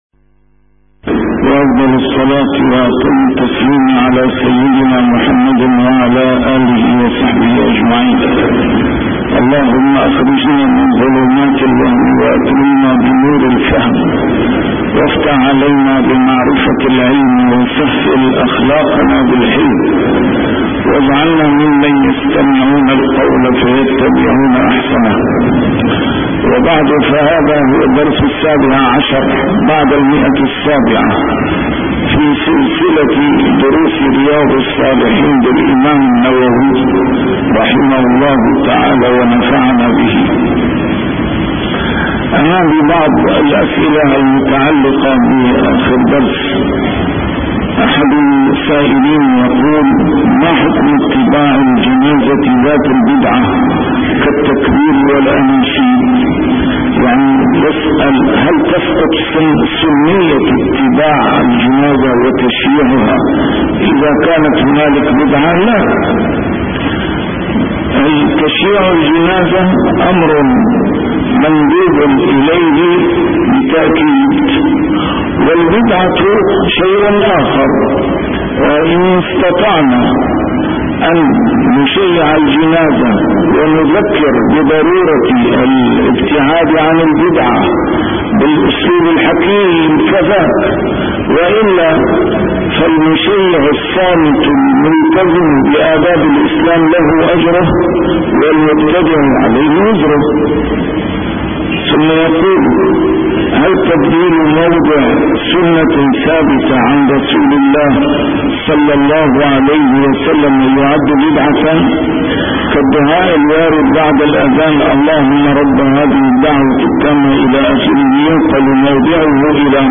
شرح كتاب رياض الصالحين - A MARTYR SCHOLAR: IMAM MUHAMMAD SAEED RAMADAN AL-BOUTI - الدروس العلمية - علوم الحديث الشريف - 717- شرح رياض الصالحين: قضاء الدين عن الميت